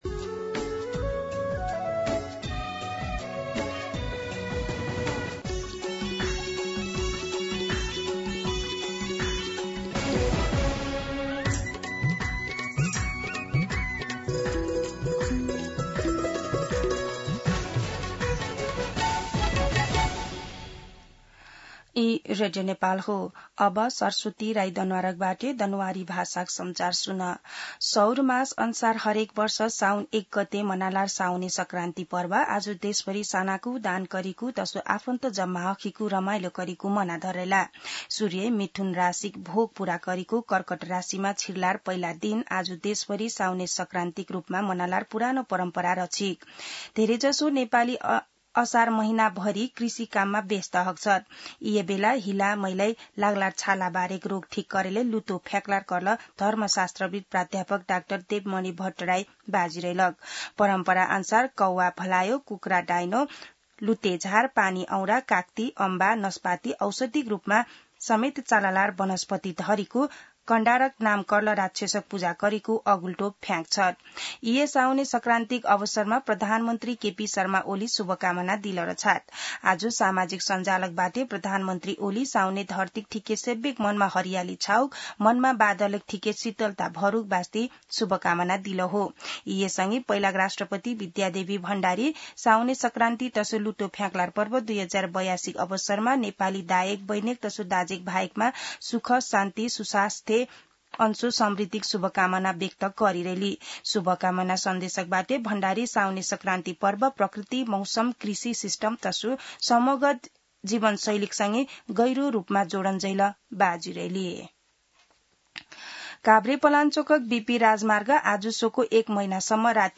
दनुवार भाषामा समाचार : १ साउन , २०८२